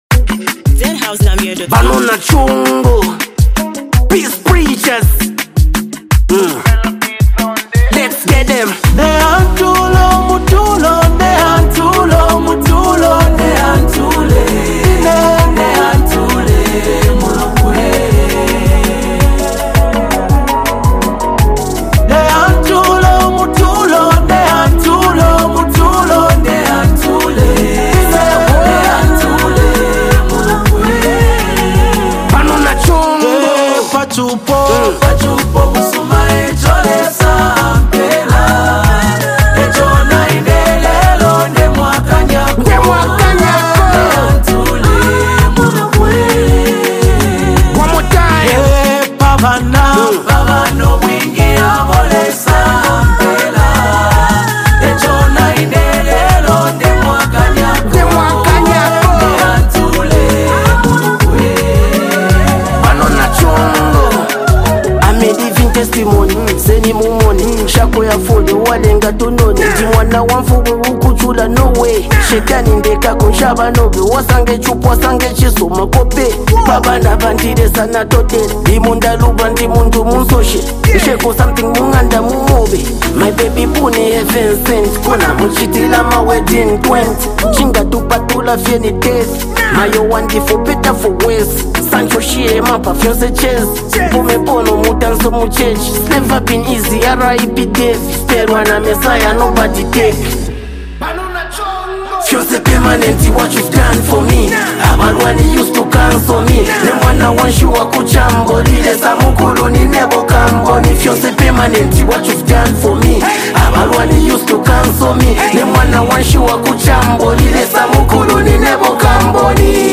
gospel vibes